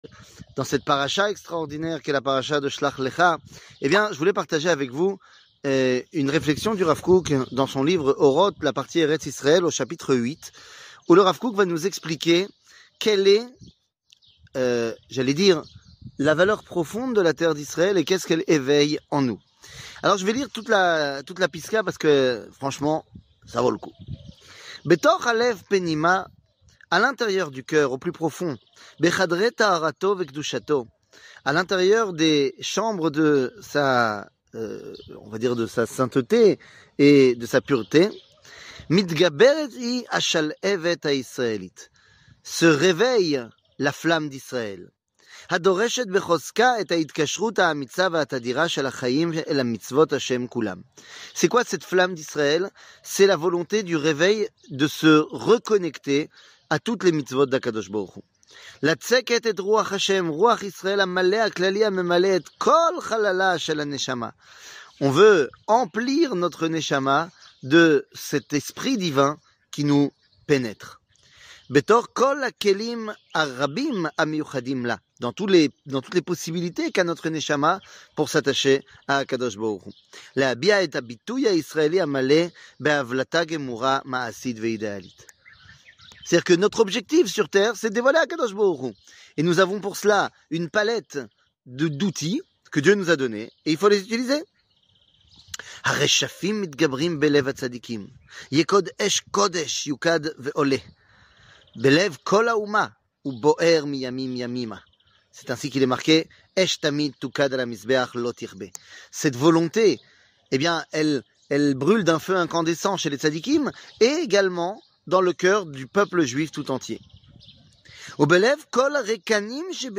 שיעור מ 14 יוני 2022